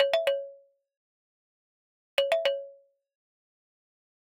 Added sound effects